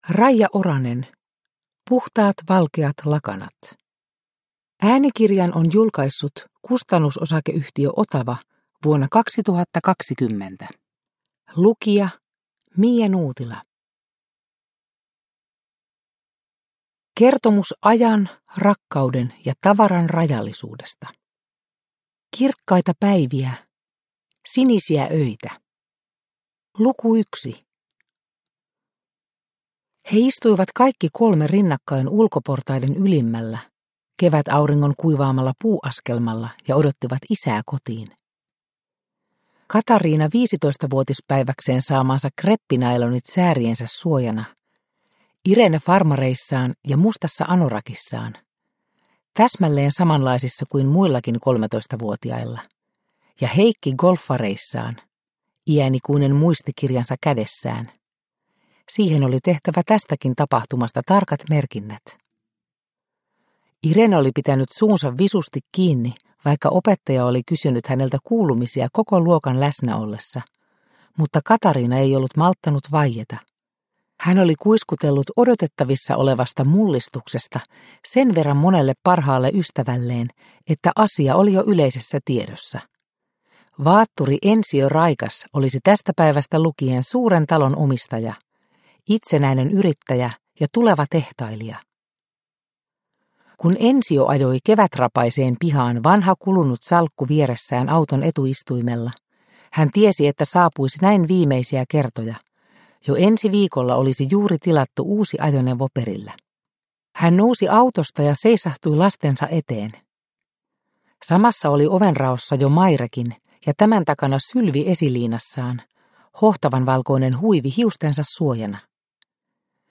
Puhtaat valkeat lakanat – Ljudbok – Laddas ner